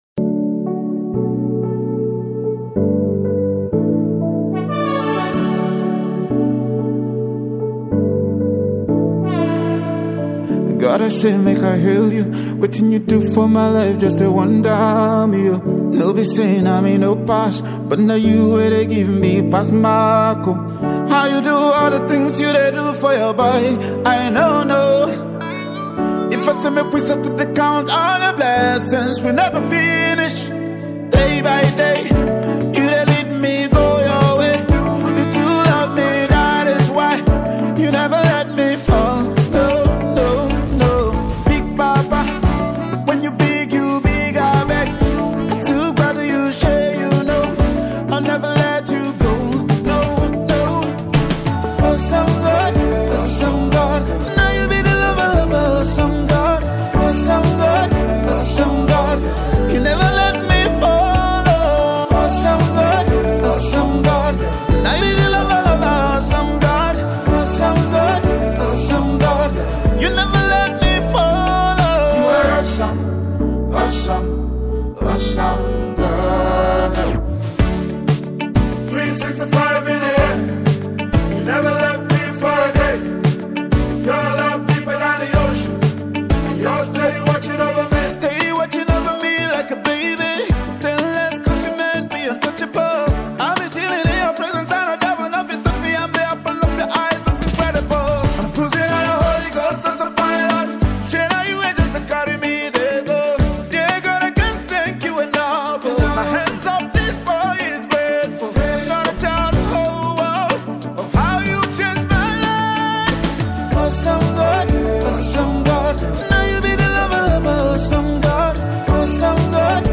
is a soulful worship song